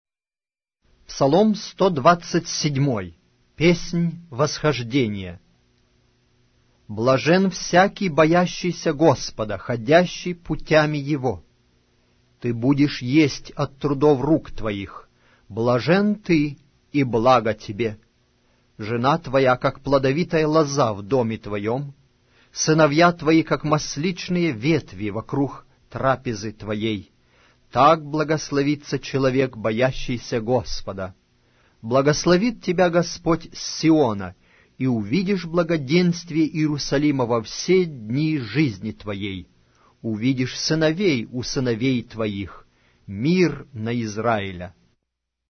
Аудиокнига: Псалтирь